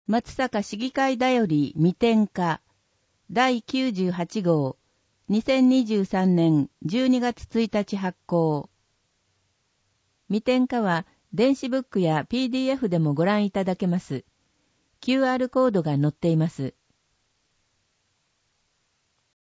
声の市議会だより
なお、この音声は「音訳グループまつさか＜外部リンク＞」の皆さんの協力で作成しています。